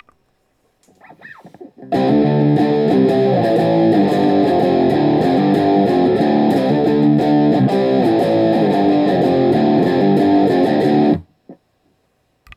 This guitar is all about articulate dark power